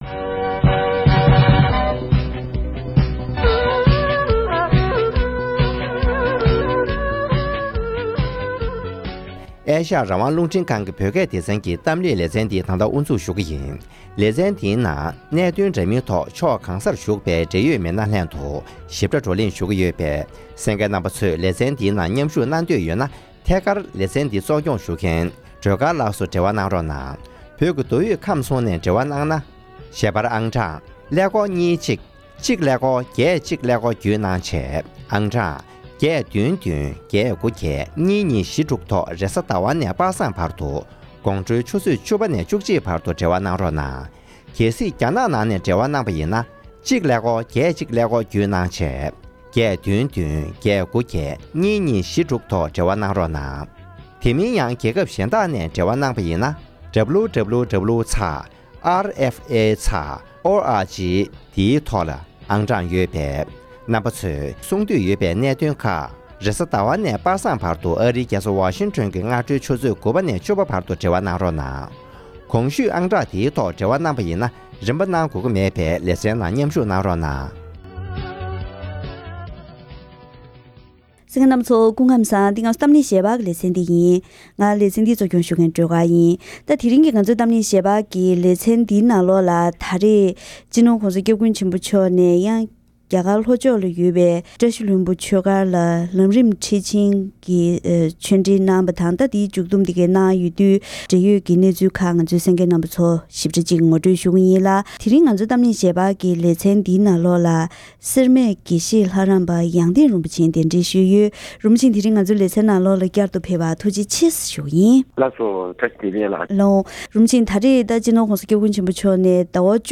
༄༅། །དེ་རིང་གི་གཏམ་གླེང་ཞལ་པར་ལེ་ཚན་ནང་ཉེ་ཆར་༧གོང་ས་མཆོག་ནས་གནང་བའི་བྱང་ཆུབ་ལམ་རིམ་གསུང་ཆོས་འདིའི་ཁྱད་ཆོས་གལ་འགངས་རང་བཞིན།